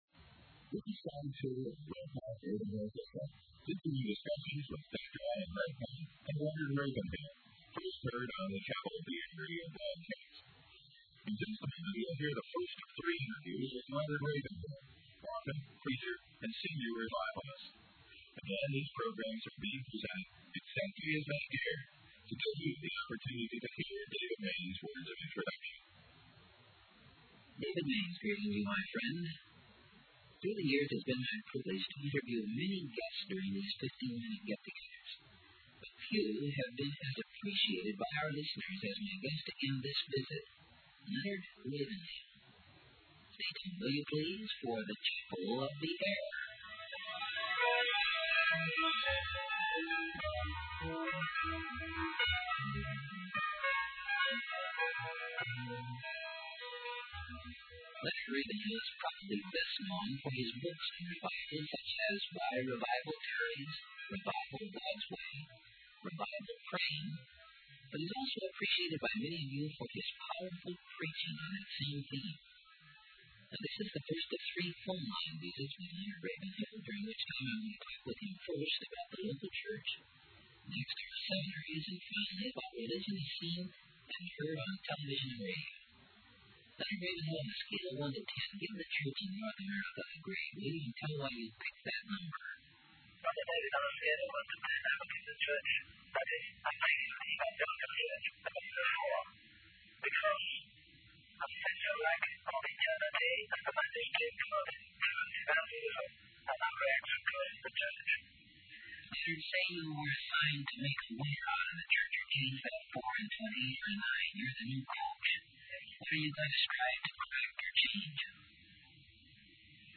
**Long Interview of Leonard Ravenhill by David Mainse by Leonard Ravenhill | SermonIndex